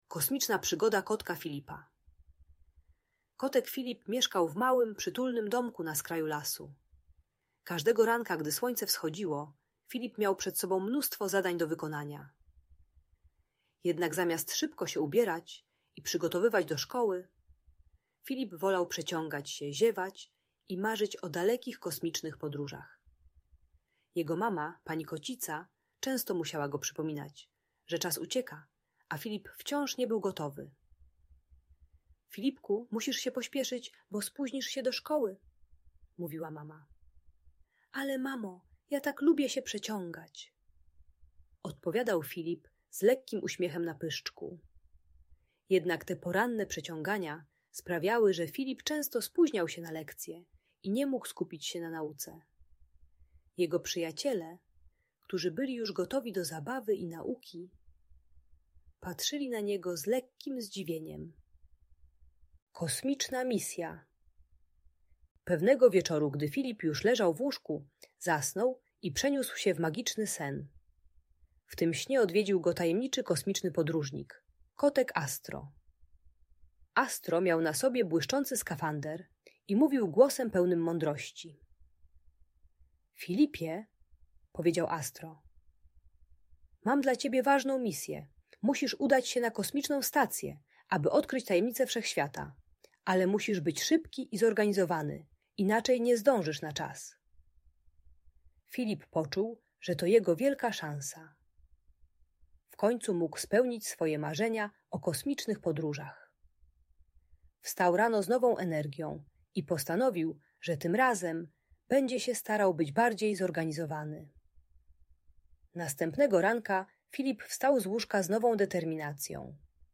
Kosmiczna Przygoda Kotka Filipa - Szkoła | Audiobajka